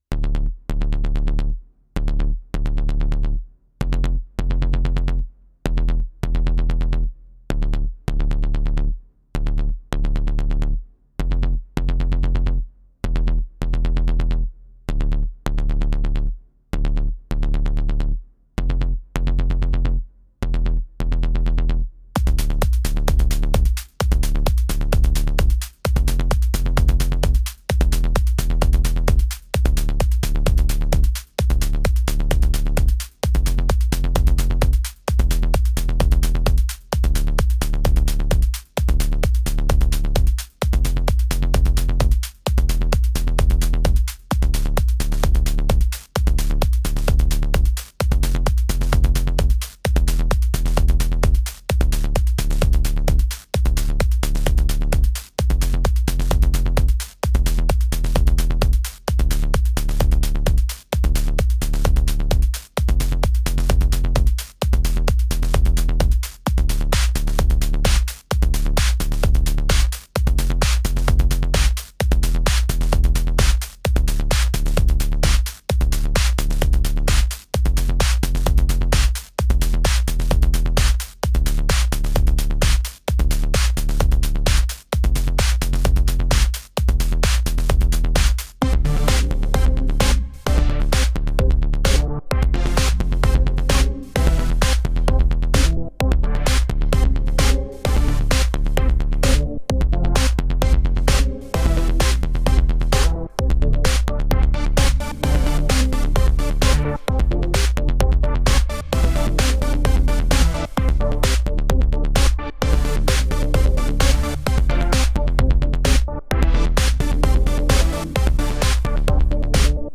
Electronic
Progressive House
Deep House
Mood: Party Music